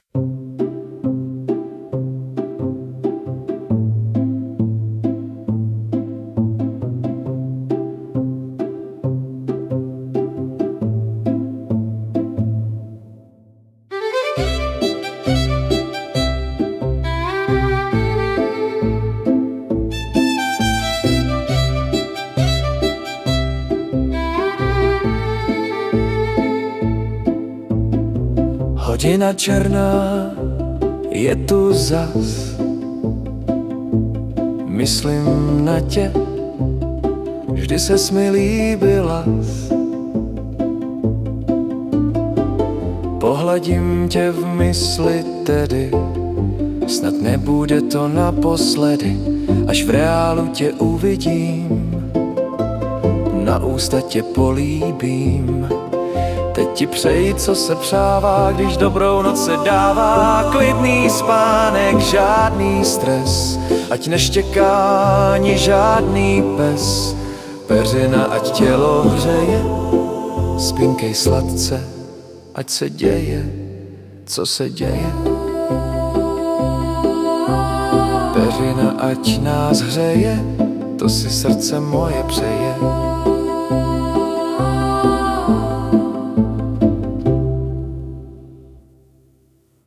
hudba, zpěv: AI
Krásná ukolébavka, líbí.